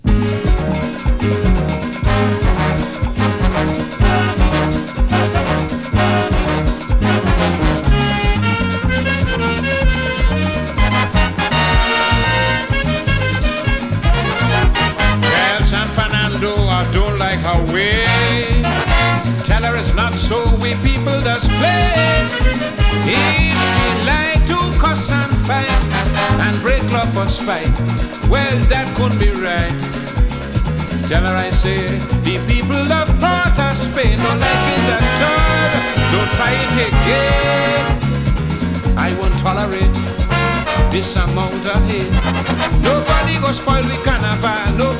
Calypso, Soca, Disco, Soul　USA　12inchレコード　33rpm　Stereo